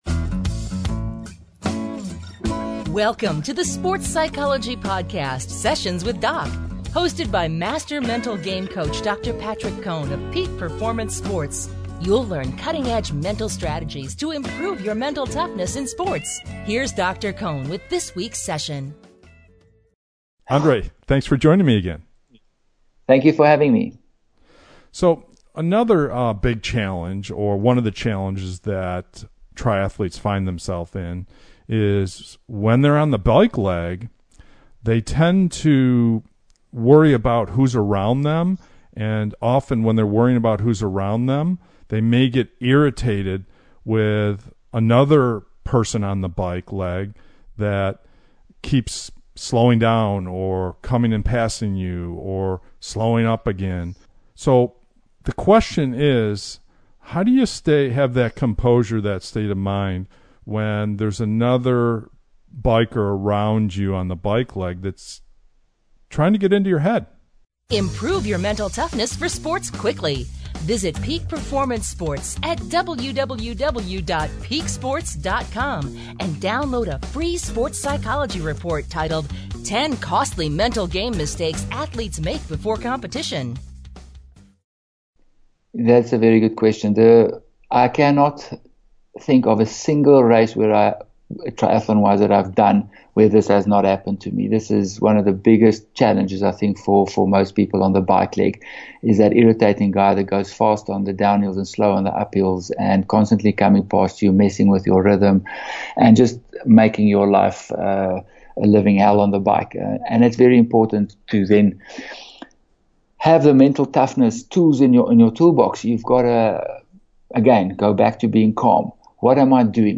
In this week’s sports psychology podcast, mental game of sports expert